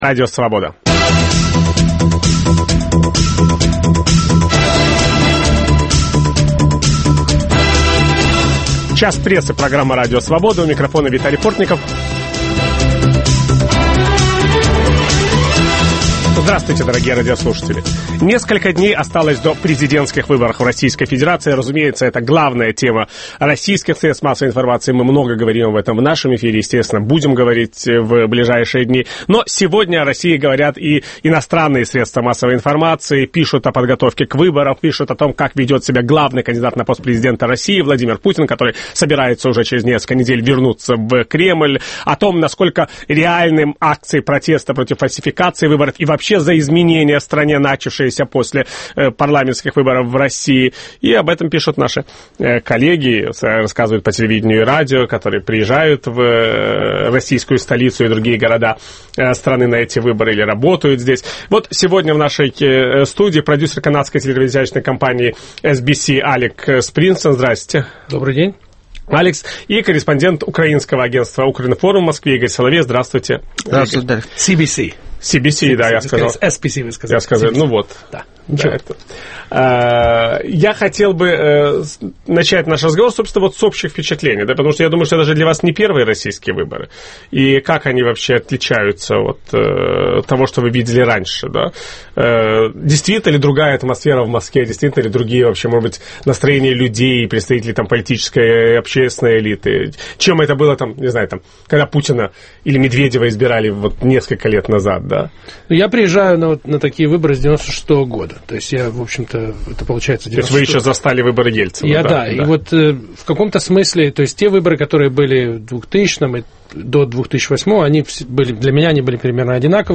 В программе дискутируют